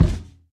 Sound / Minecraft / mob / irongolem / walk1.ogg
walk1.ogg